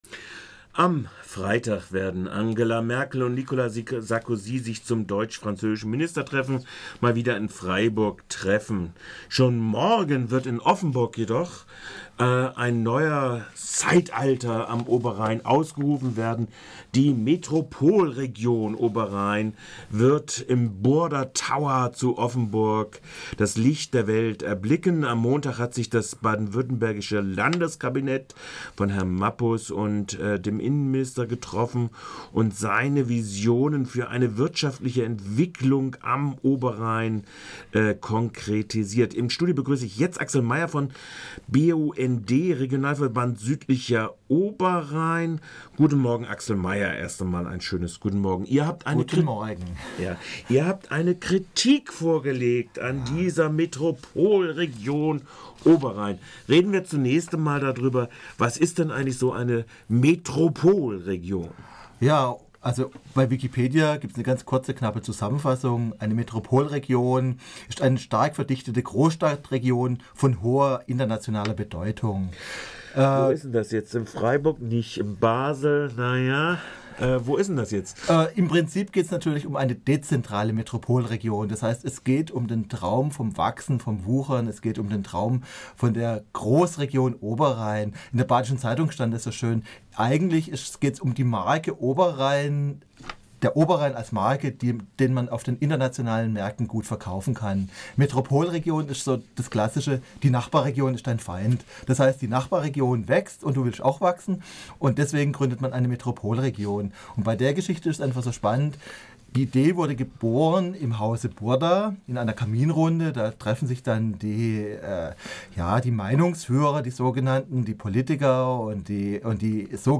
"Metropolregion" am Oberrhein - Eine Kritik im Studiogespräch